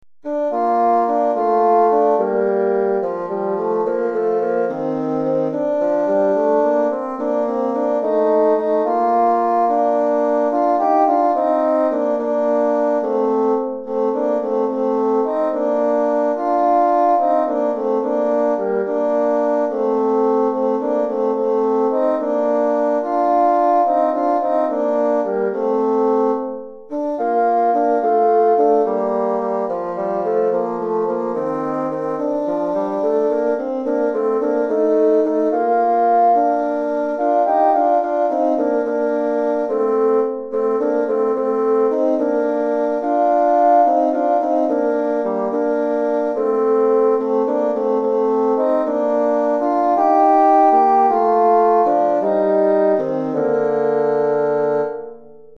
Recueil pour Basson - 2 Bassons